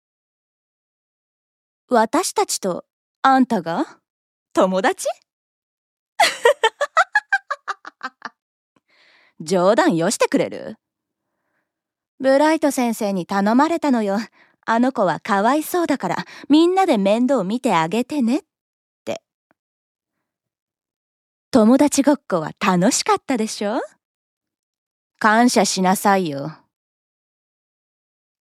◆10代女子(悪役)◆